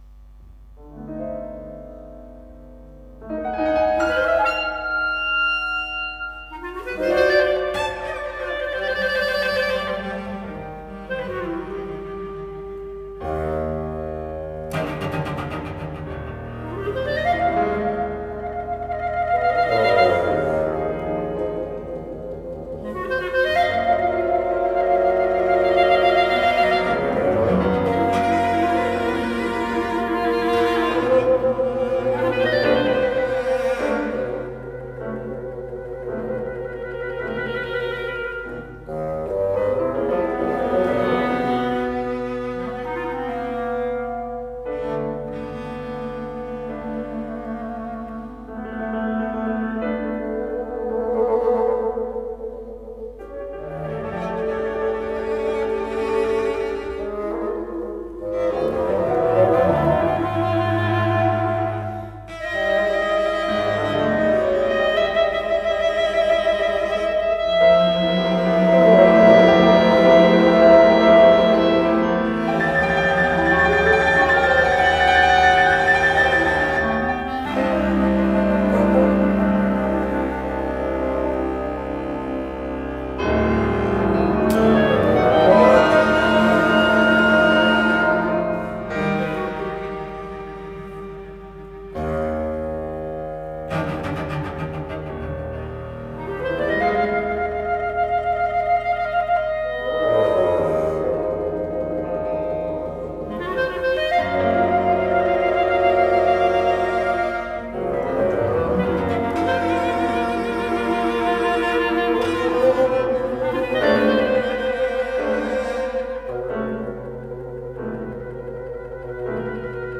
muzyka serialna
współczesne utwory kameralne